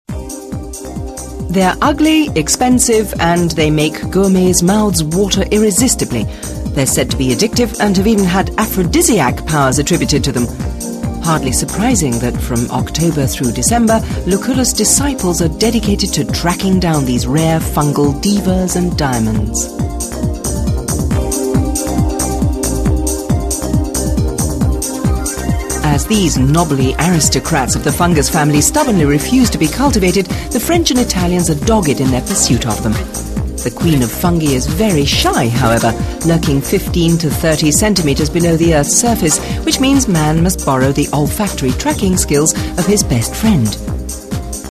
englische Synchron-Sprecherin.
Sprechprobe: Industrie (Muttersprache):
english female voice over artist